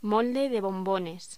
Locución: Molde de bombones
voz